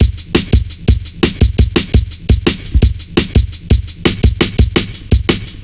Index of /90_sSampleCDs/Zero-G - Total Drum Bass/Drumloops - 3/track 62 (170bpm)